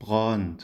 hochdeutsch Gehlbergersch
Am Brand   Braand